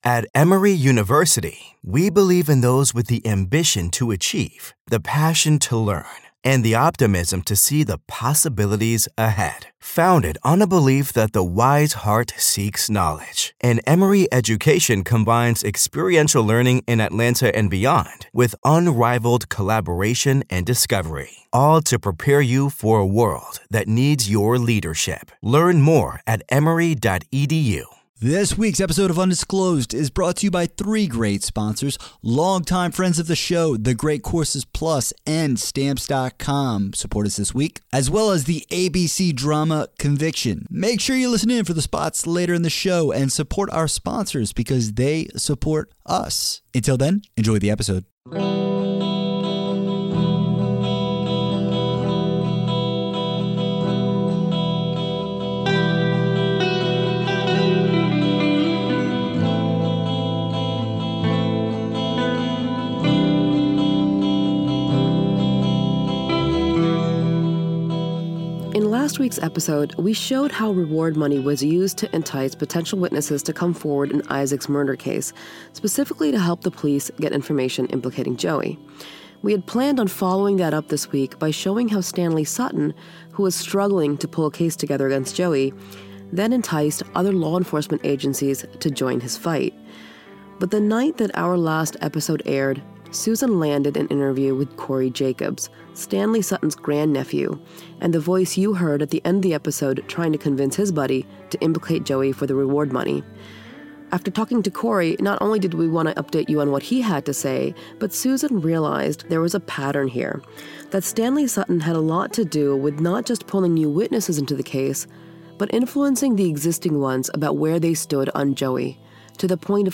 Episode scoring music